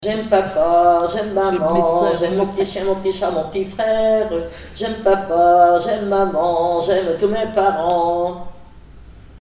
Comptines et formulettes enfantines
Pièce musicale inédite